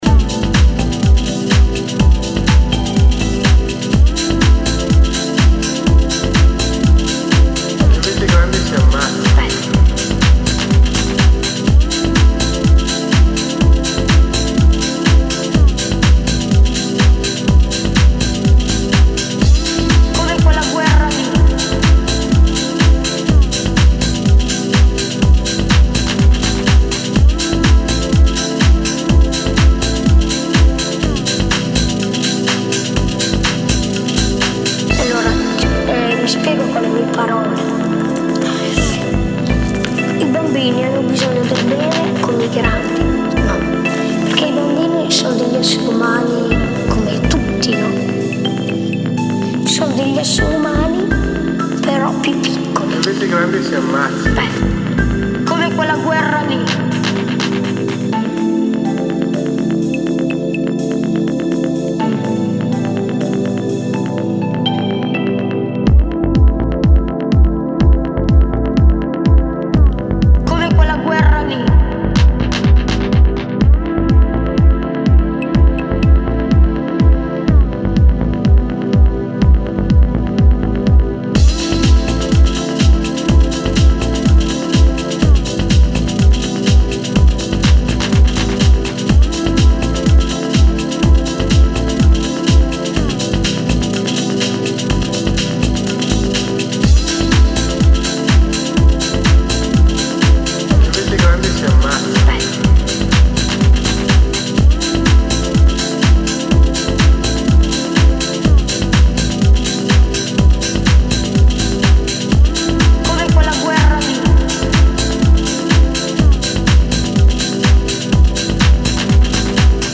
introduces a lighthearted mood and 80s synthesizers